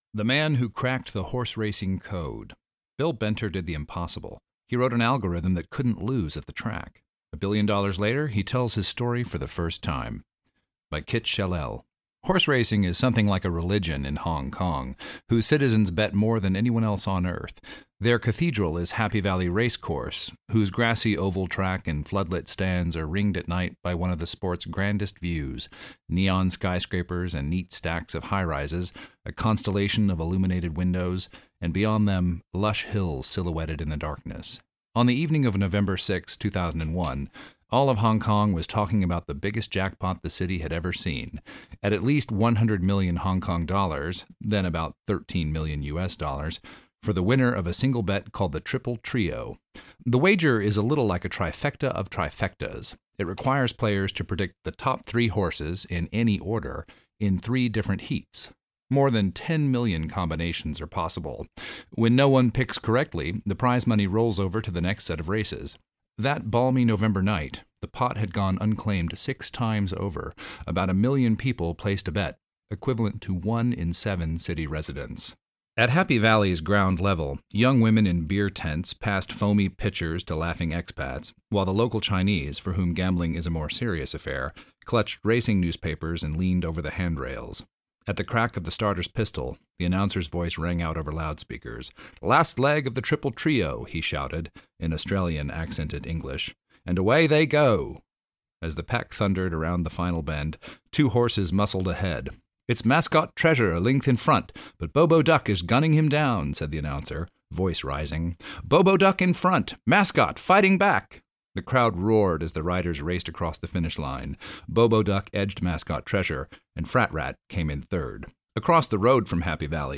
The Man Who Cracked the Horse-Racing Code, Read Aloud by[Mp3].mp3